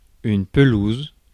Ääntäminen
France: IPA: /pə.luz/